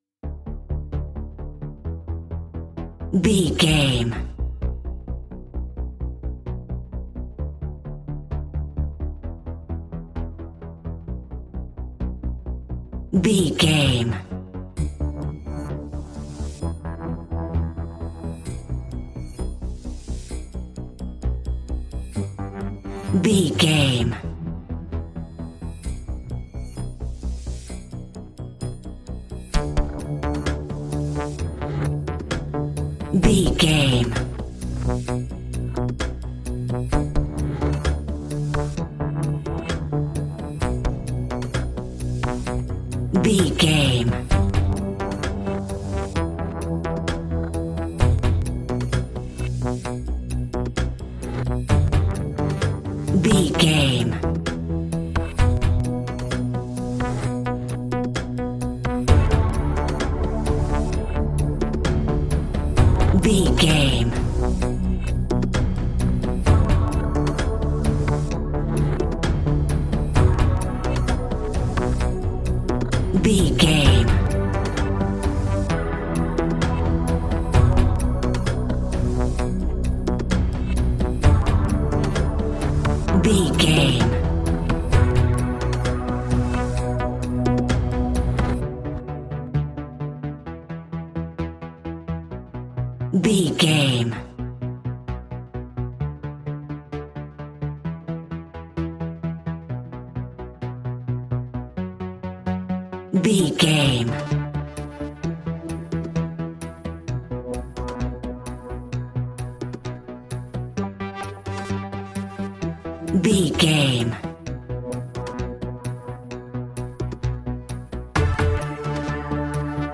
In-crescendo
Thriller
Aeolian/Minor
scary
ominous
dark
haunting
eerie
synthesiser
drums
instrumentals
horror music
Horror Synths